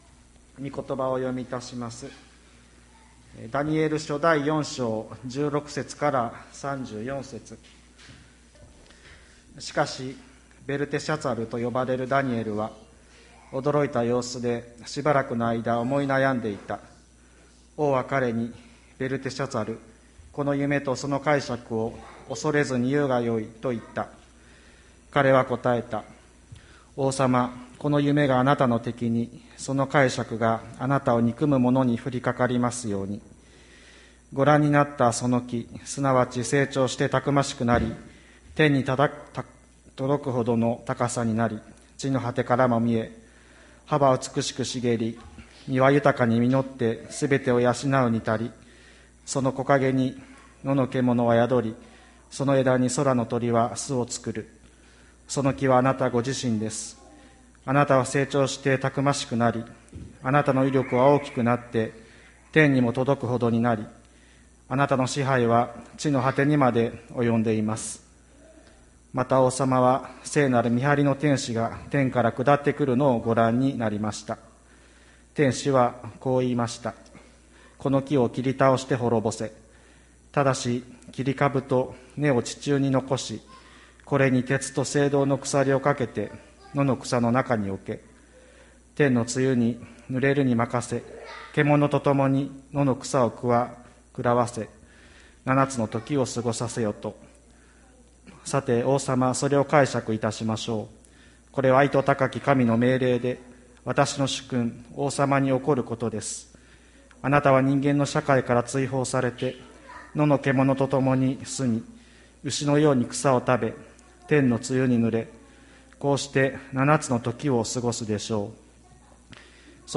千里山教会 2020年08月23日の礼拝メッセージ。